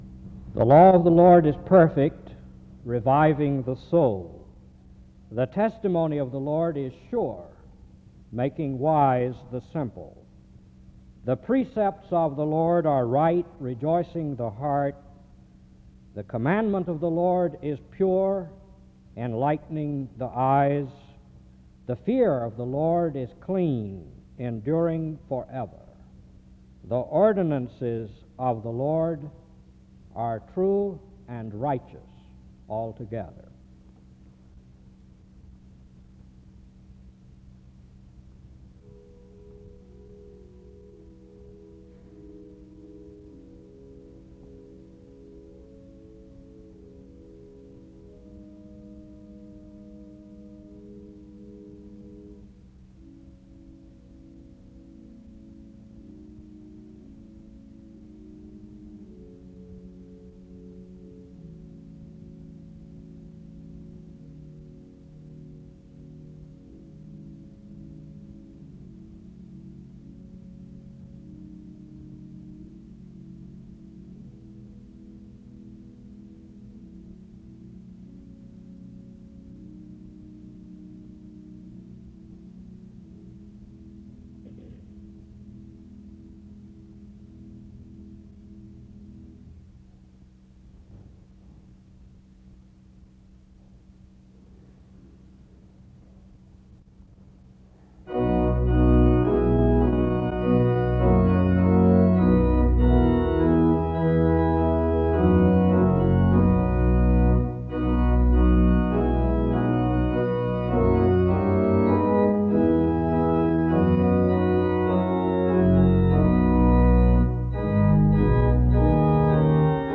The service starts with an opening scripture reading form 0:00-0:35. Music plays from 0:39-5:34. A prayer is offered from 5:41-7:15. An introduction to the speaker is given from 7:20-8:24.